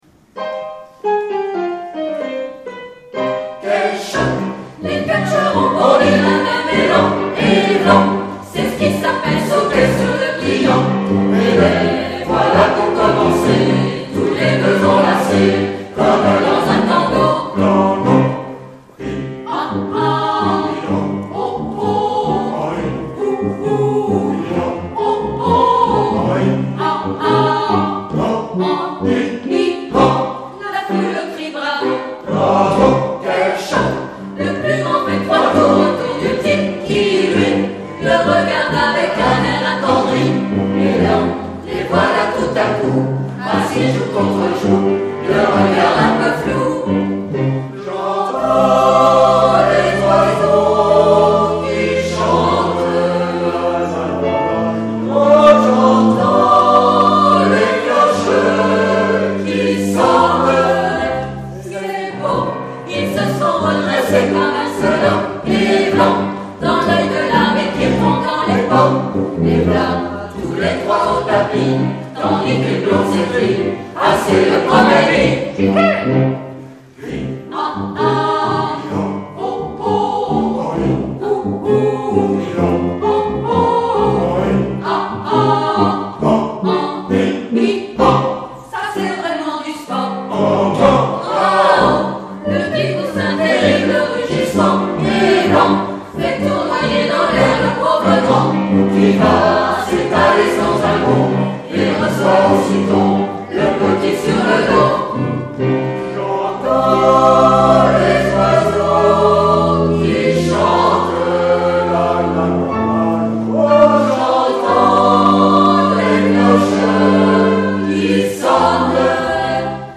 Temple Protestant
Concert du 27 avril 2007
extrait musical